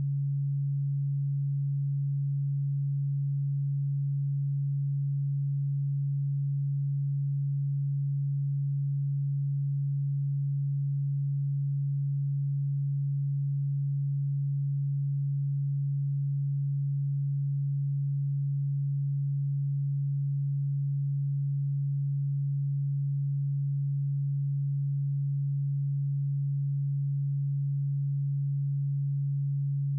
140Hz_-26.dB.wav